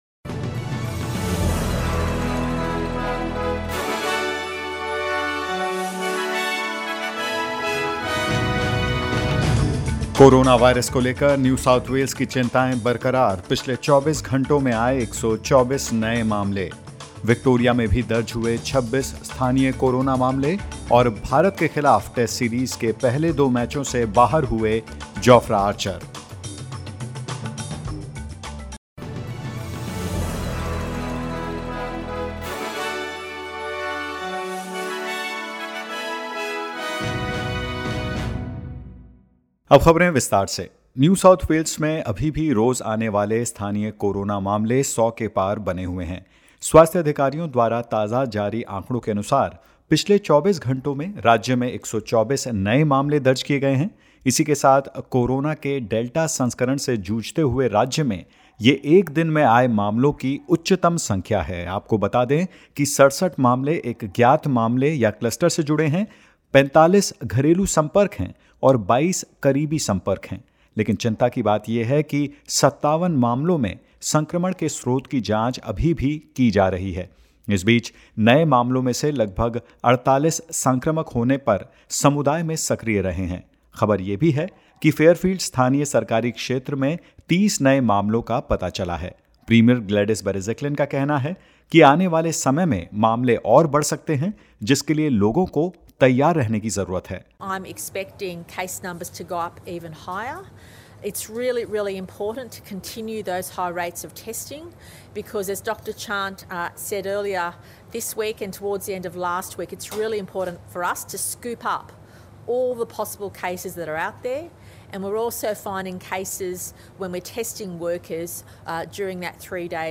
In this latest SBS Hindi News bulletin of Australia and India: The Prime Minister apologises for problems with Australia's Covid-19 vaccination rollout; With only two infectious in the community, Victoria records 26 new local COVID-19 cases and more.